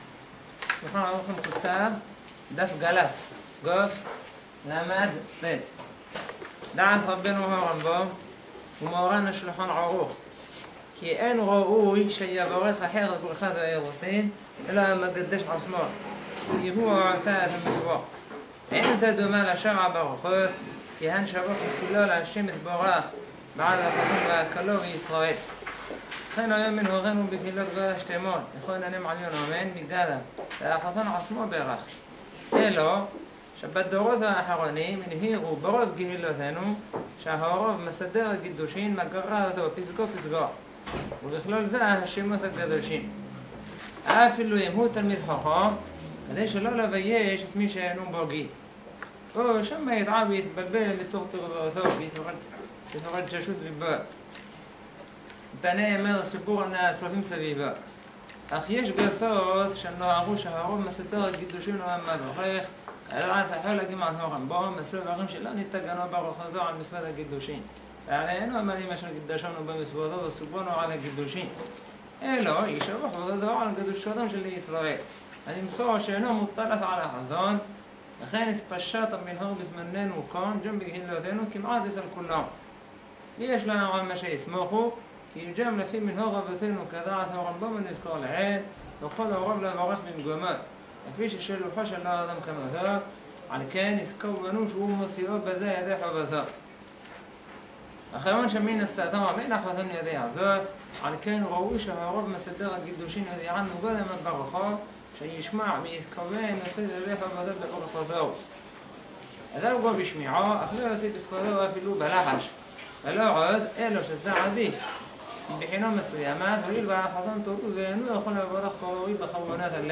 נמסר במסגרת השיעור המקדים